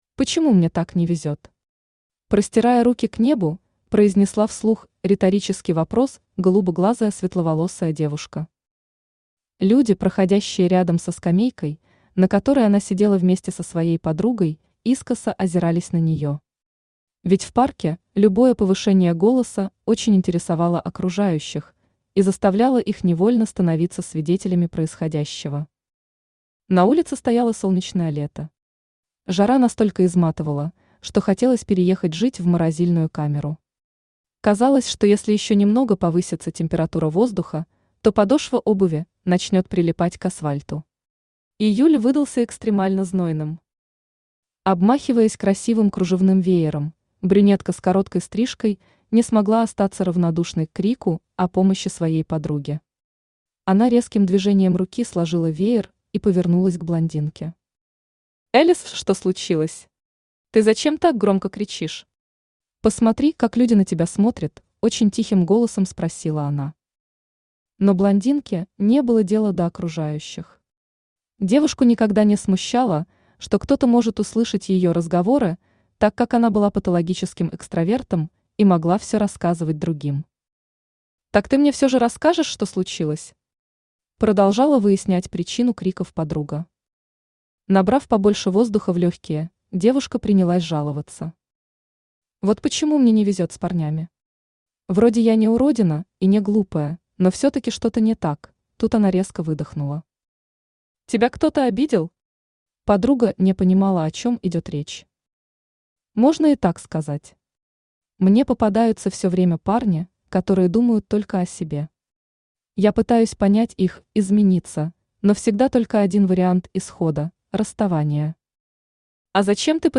Аудиокнига Магическое приложение | Библиотека аудиокниг
Aудиокнига Магическое приложение Автор Сирена Рэд Читает аудиокнигу Авточтец ЛитРес.